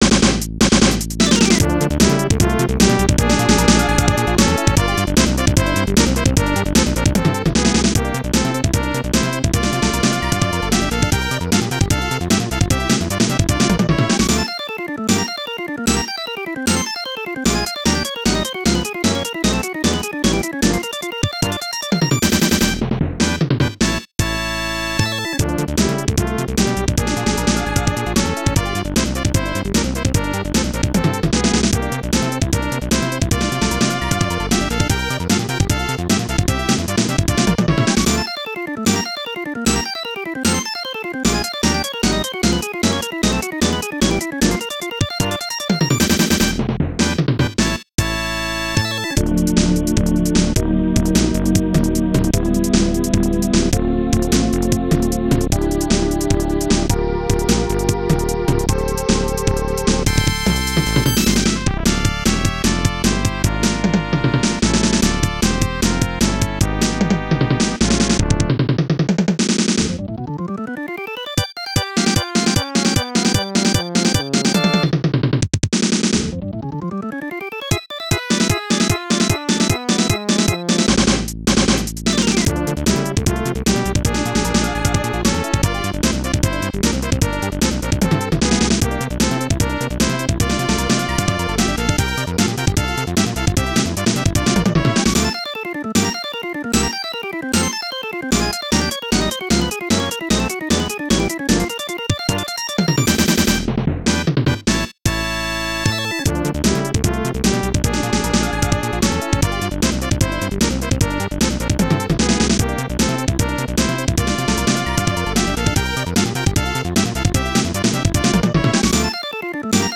BPM151-151
Audio QualityPerfect (High Quality)
boss music